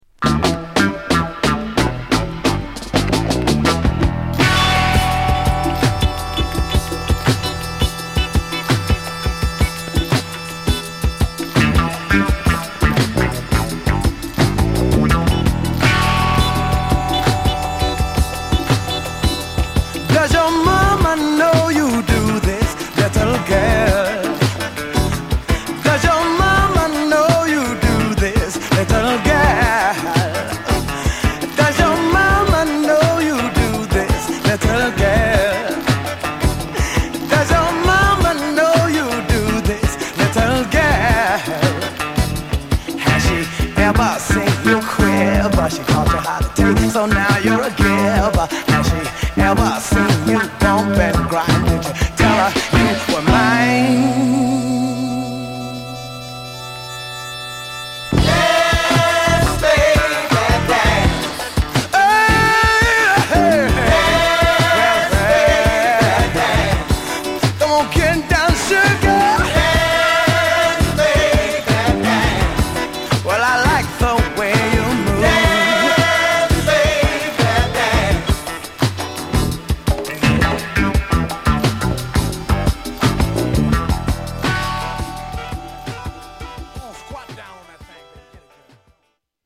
Aサイド頭に小さな傷有り。ほんの少し音に出ます。